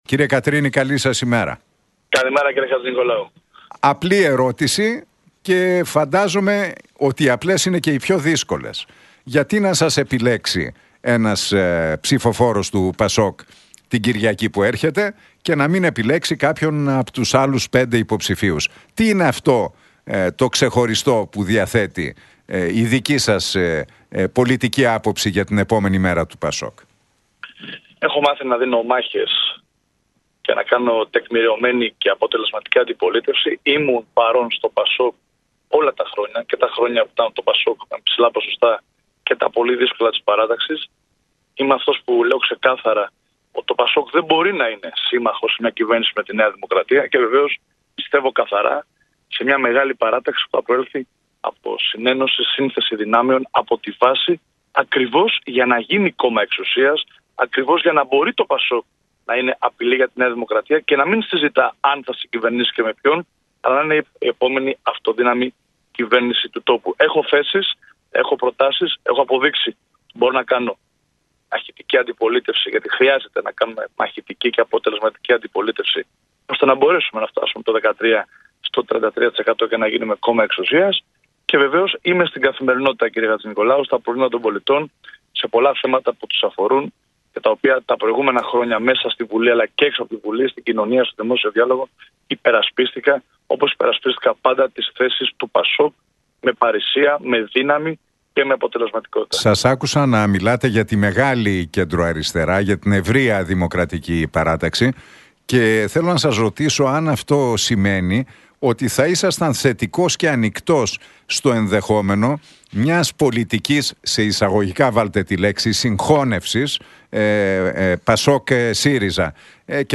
«Δεν συζητώ κανένα ενδεχόμενο συνεργασίας με τη ΝΔ και τον κ. Μητσοτάκη» τόνισε ο Μιχάλης Κατρίνης μιλώντας στον Realfm 97,8 και την εκπομπή του Νίκου Χατζηνικολάου.